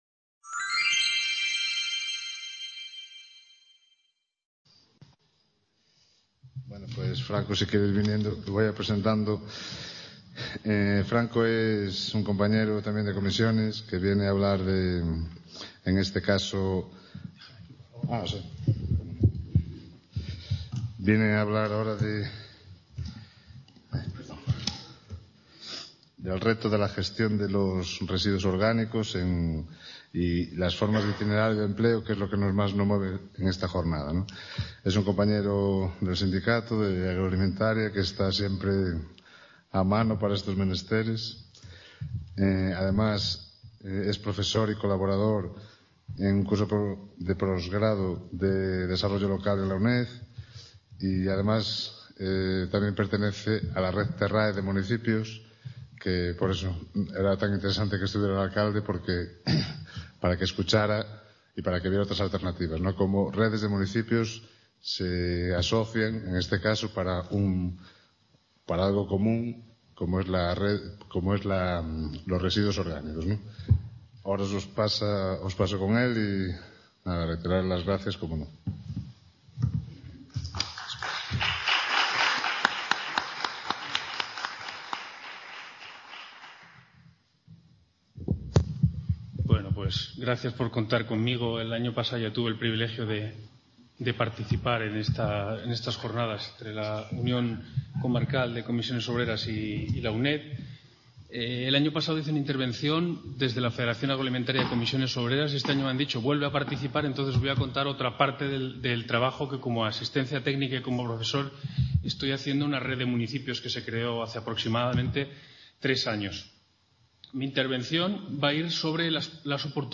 C.A. Ponferrada. II Jornadas Otra Economía es Posible: Gestión de residuos y creación de empleo.